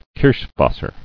[kirsch·was·ser]